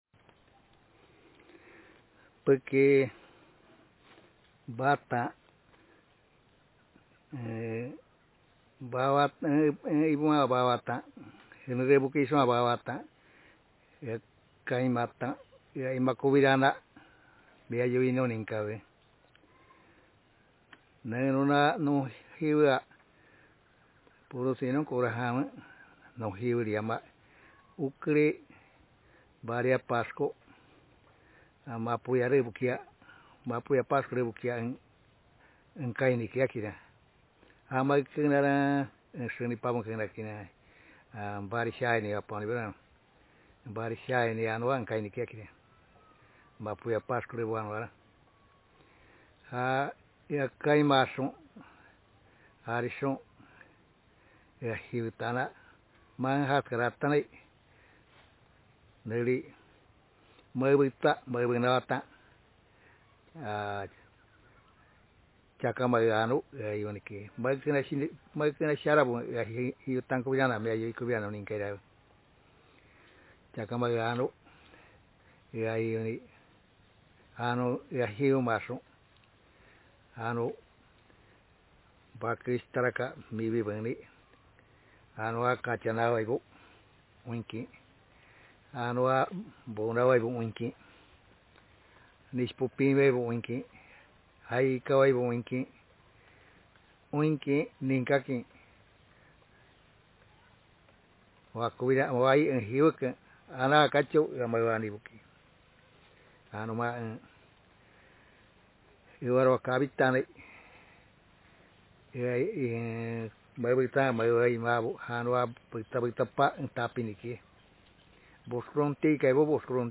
Speaker sex m Text genre personal narrative